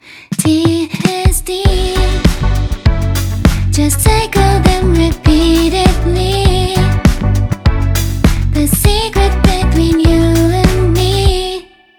さてそれでは、ここから怒涛の視聴タイムです!! 4コードのループで、TDSの違いを感じてみましょう。今回は単一のメロディを使い回して、さまざまなコード進行をあてていくことにします。
ここまで聴いてきたI始まりの「明るくて安定的」ともVIm始まりの「暗くて安定的」とも違う、ちょっとフワッとした始まり方をしているのが分かるでしょうか？